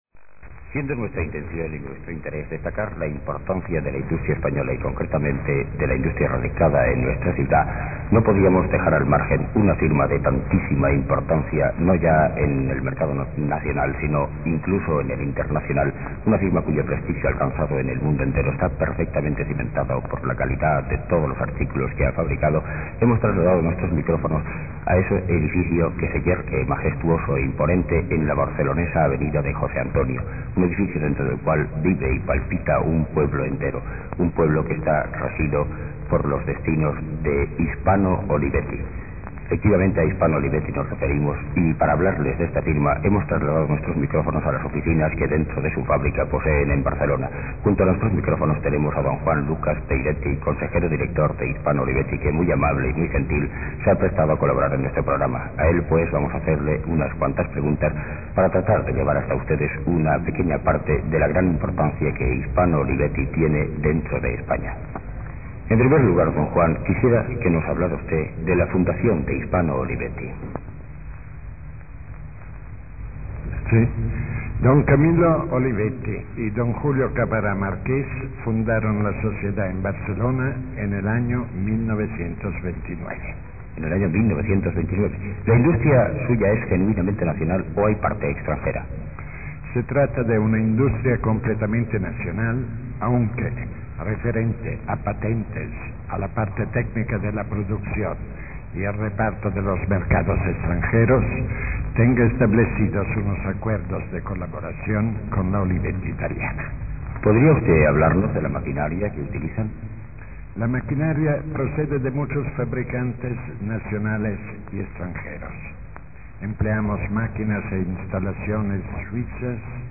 Entrevista
Grabación del  Año 1959
En su interior, un extraño disco microsurco de 45 r.p.m. con la sucinta inscripción: Hispano Olivetti 1959.